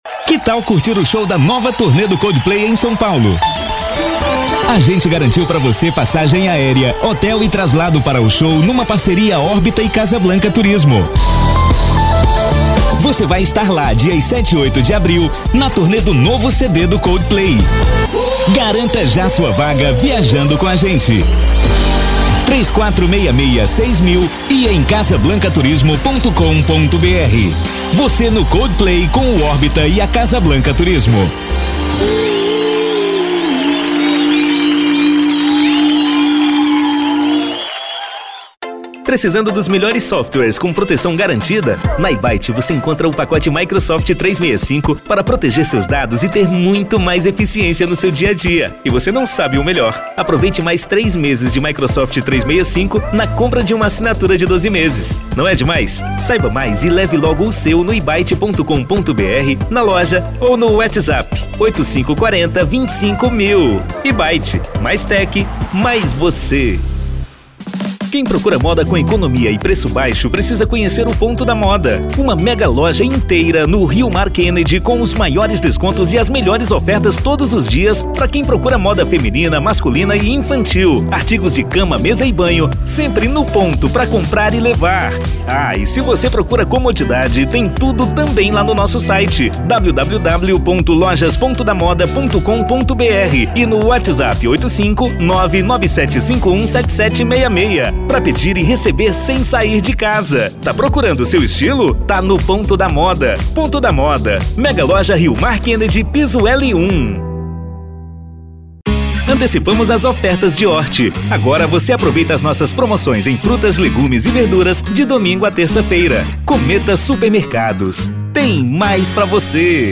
Spot Comercial
Vinhetas
Animada